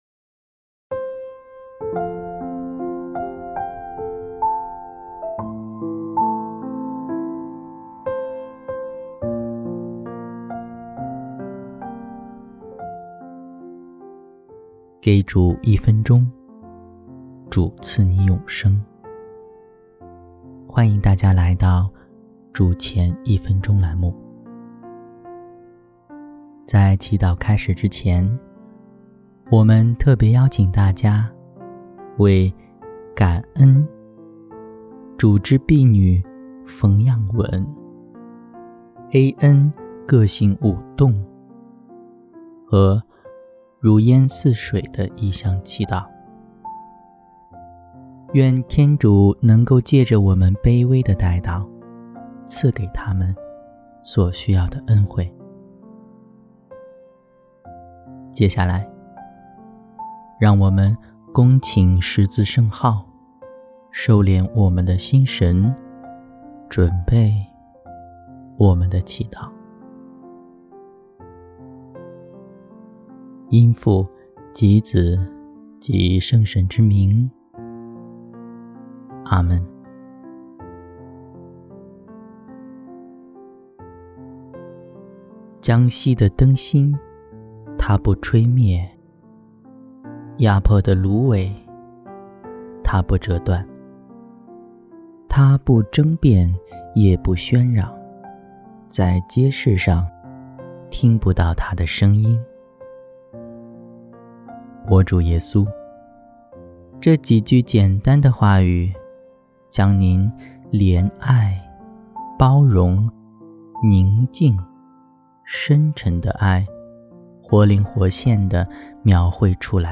7月17日祷词